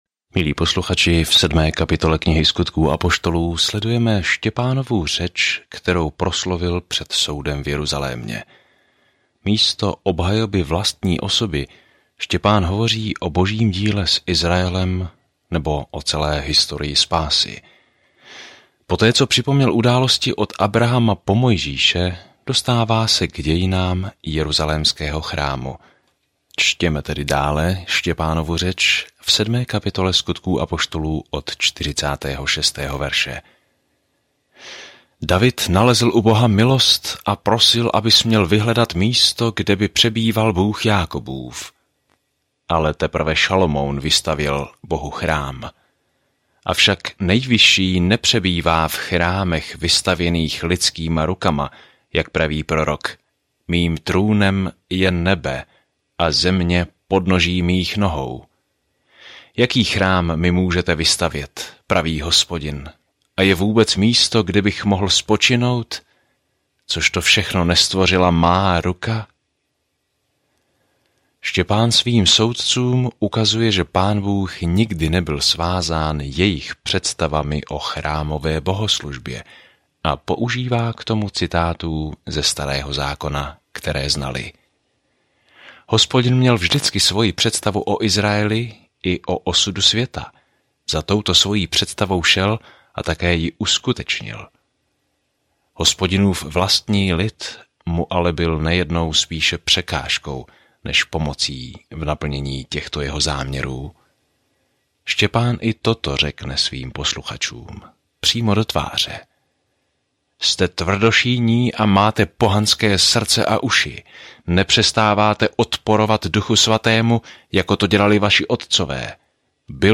Písmo Skutky 7:46-60 Skutky 8:1-17 Den 6 Začít tento plán Den 8 O tomto plánu Ježíšovo dílo započaté v evangeliích nyní pokračuje skrze jeho Ducha, zatímco je církev zakládána a roste po celém světě. Denně procházejte Skutky a poslouchejte audiostudii a čtěte vybrané verše z Božího slova.